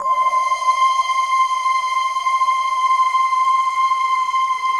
Index of /90_sSampleCDs/Optical Media International - Sonic Images Library/SI1_RainstickChr/SI1_RainstickMix